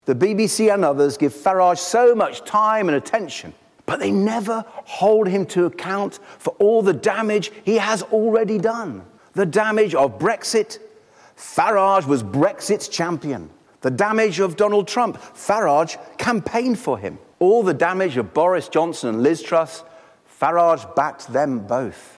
Lib Dem leader Sir Ed Davey addresses his party conference.